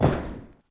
scpcb-godot/SFX/Character/MTF/Step1.ogg at 81ef11964c8ee75b67faf28b3237c393a288471c
Step1.ogg